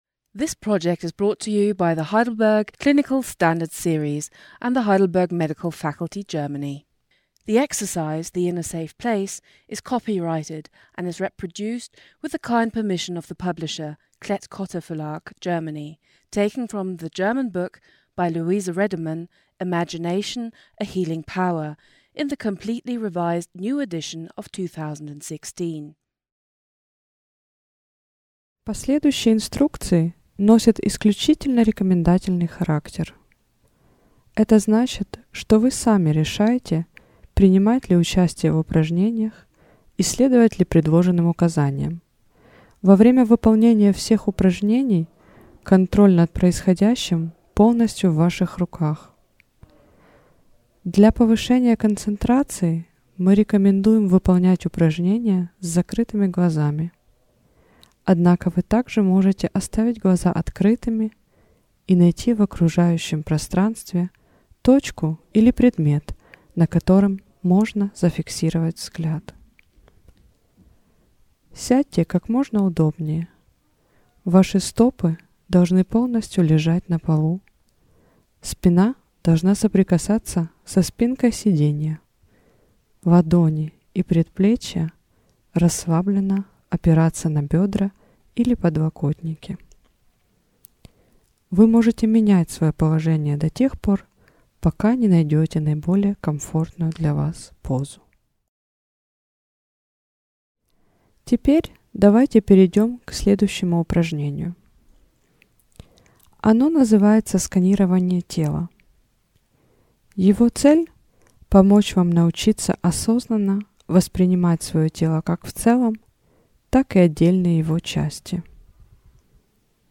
Russisch_Bodyscan.mp3